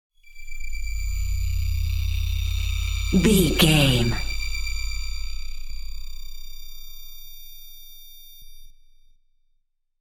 Atonal
tension
ominous
dark
haunting
eerie
industrial
synthesiser
ambience
pads
eletronic